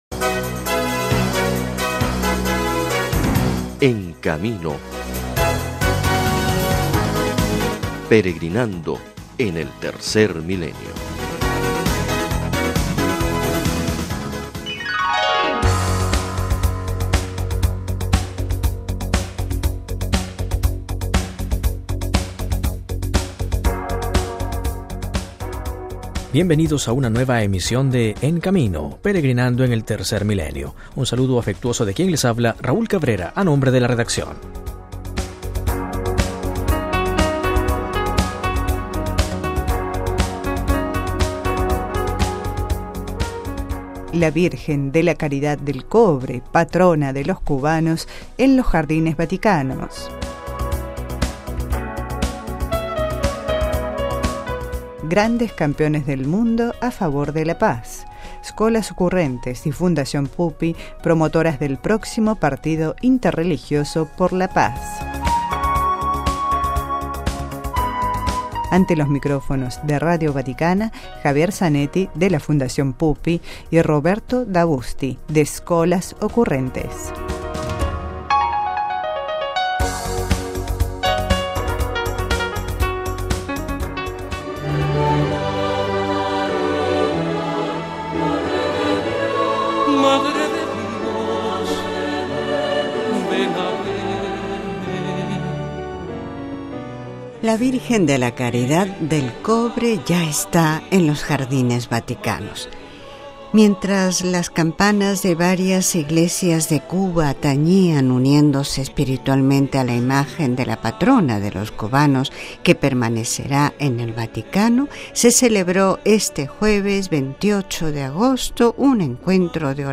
(RV).- (Con audio) En el programa “En Camino” escuchamos las voces de Papa Francisco en la audiencia general de este miércoles, cuando anuncia la llegada el jueves 28 de agosto en Vaticano de una copia de la imagen de Virgen de la Caridad del Cobre, patrona de Cuba, y del Papa Emérito Benedicto XVI que también se sumó a la alegría de este momento solemne y familiar, haciendo llegar sus saludos, en el recuerdo de cuando como peregrino de la Caridad, llegó al Santuario de «la Mambisa».